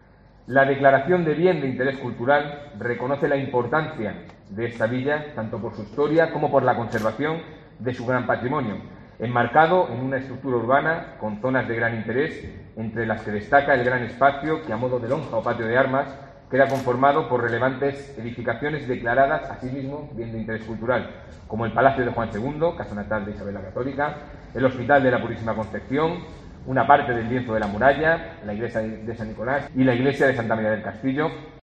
Consejero de Cultura. Madrigal de las Altas Torres